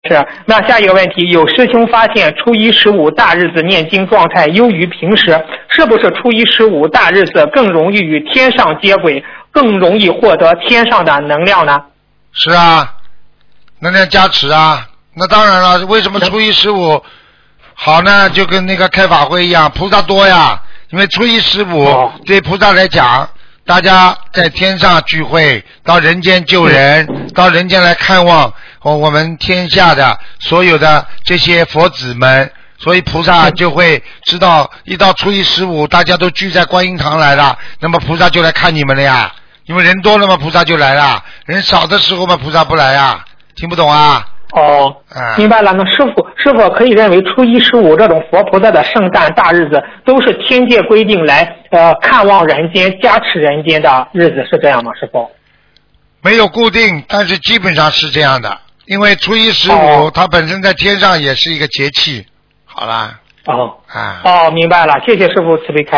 Tanya Jawab